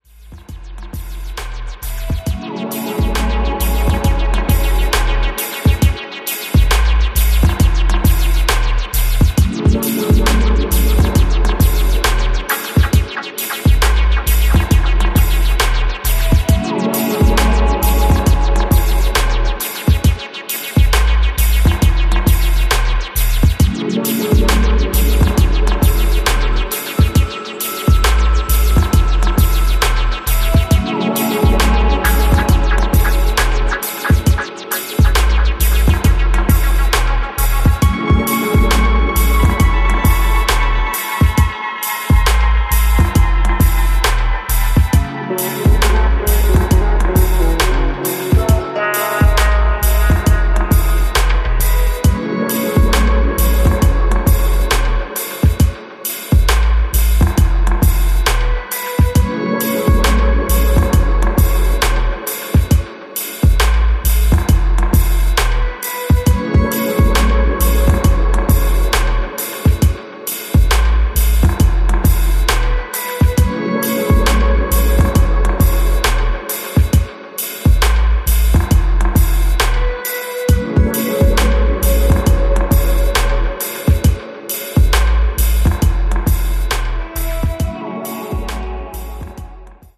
B-2に収録されているダウンテンポ・ダブもナイス。